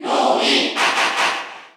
Category: Crowd cheers (SSBU) You cannot overwrite this file.
Roy_Cheer_Korean_SSBU.ogg